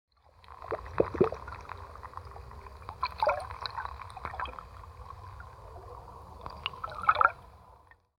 دانلود آهنگ آب 33 از افکت صوتی طبیعت و محیط
دانلود صدای آب 33 از ساعد نیوز با لینک مستقیم و کیفیت بالا
جلوه های صوتی